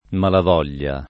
vai all'elenco alfabetico delle voci ingrandisci il carattere 100% rimpicciolisci il carattere stampa invia tramite posta elettronica codividi su Facebook malavoglia [ malav 0 l’l’a ] o mala voglia [ id. ] s. f. — sim. il cogn.